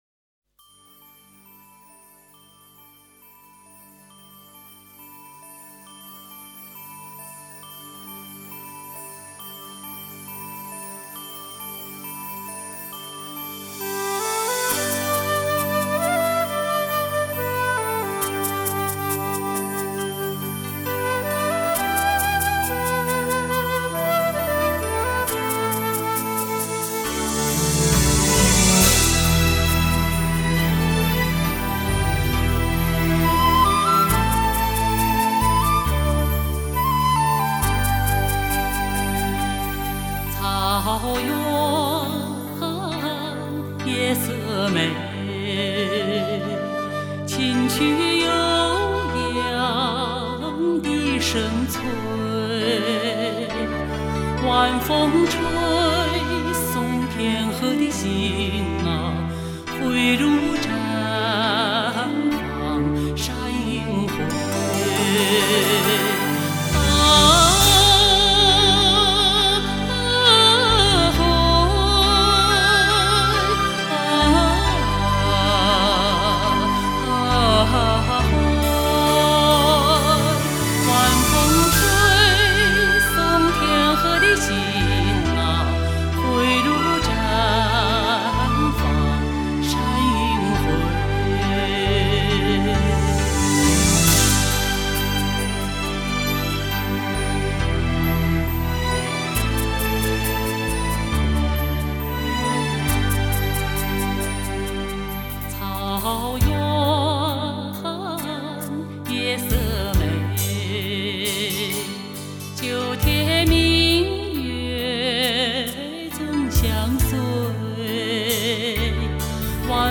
录音在1997年，是这个系列中音响效果最好的一个了。